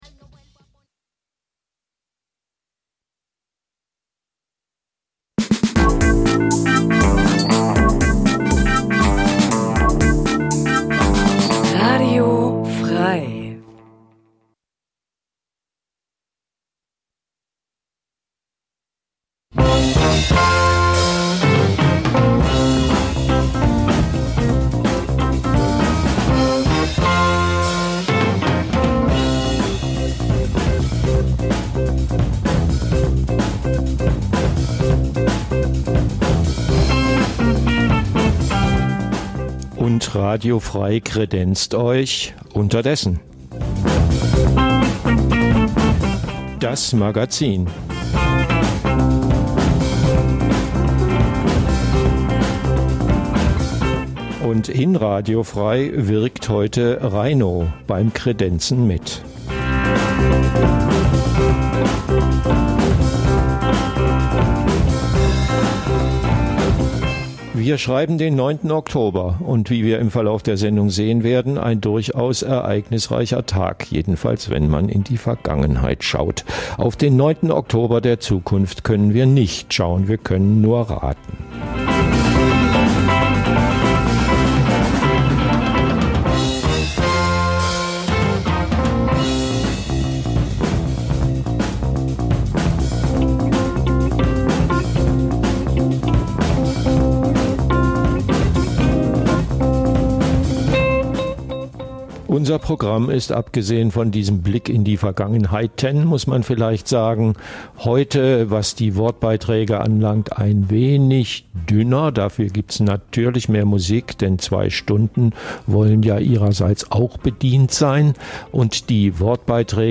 Das tagesaktuelle Livemagazin sendet ab 2024 montags bis freitags 9-11 Uhr.